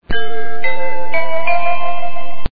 The sound bytes heard on this page have quirks and are low quality.
JETSON'S DOORBELL 2.36